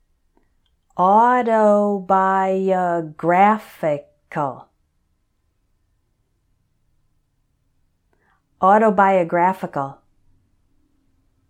So for these words I’ll say them once slowly and once normally, so you can repeat both times.
au – to – bi – o – GRAPH – i – cal……….. autobiographical